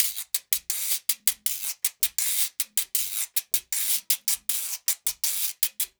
80 GUIRO 2.wav